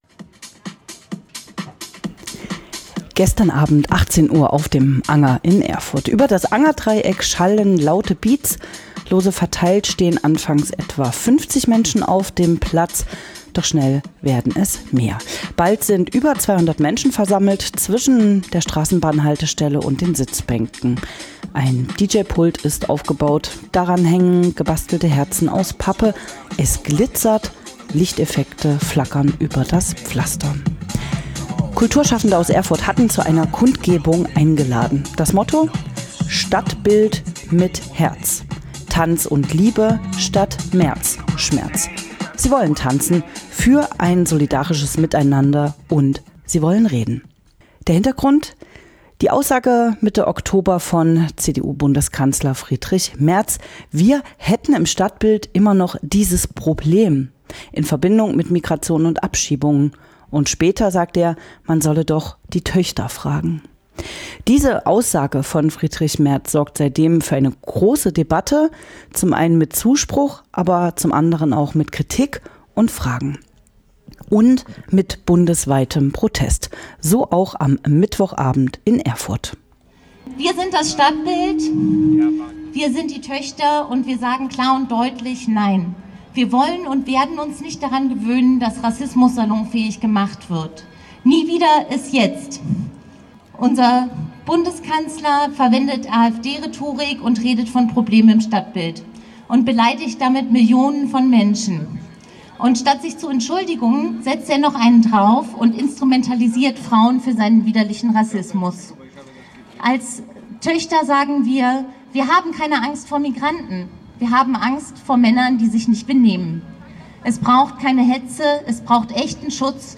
"Wir sind das Stadtbild" | Eindrücke von der Kundgebung am 29.10. auf dem Erfurter Anger
Die "Stadtbild"-Aussagen von Bundeskanzler Friedrich Merz sorgen auch in Erfurt für Kritik und Protest. Kulturschaffende aus Erfurt hatten am 29. Oktober zu einer Kundgebung mit Tanz aufgerufen. Radio F.R.E.I. war vor Ort zwischen Redebeiträgen aus migrantischer Perspektive und DJ-Musik.